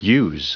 Prononciation du mot us en anglais (fichier audio)
Prononciation du mot : us